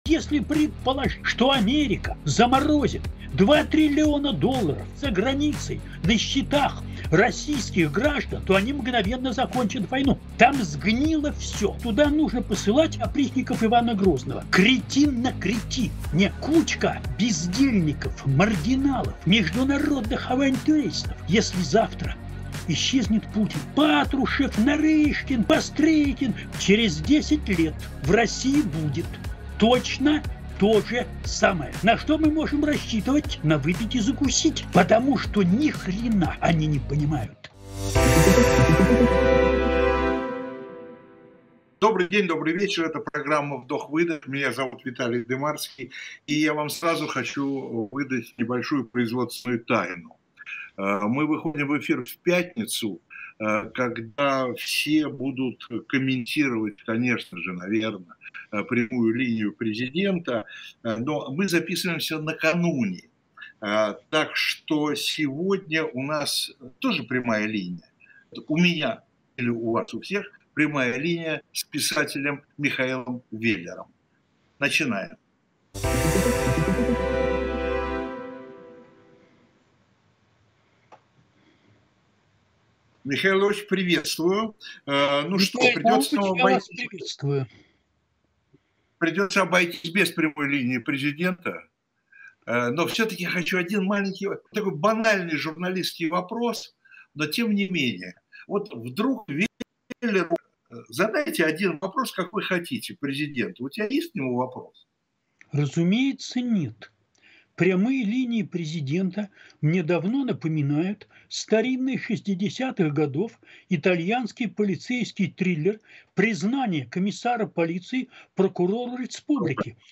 Программу ведет Виталий Дымарский